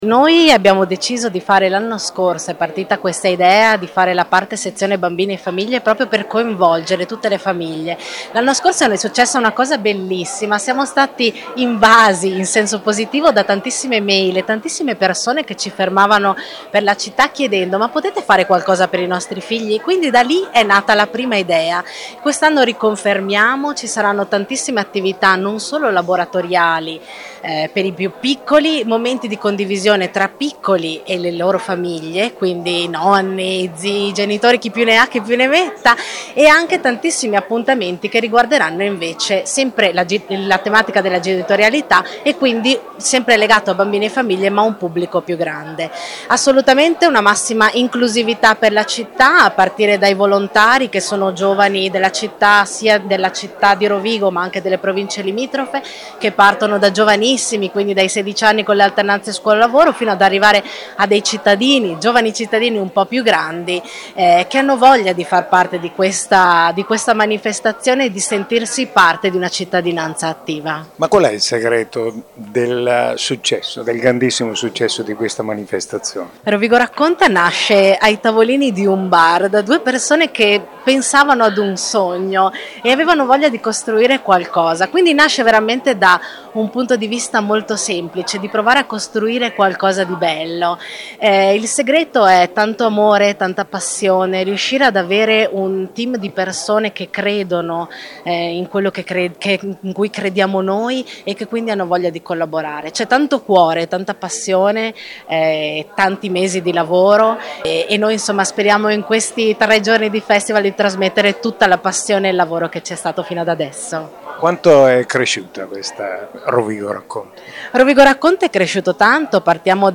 Al microfono del nostro corrispondente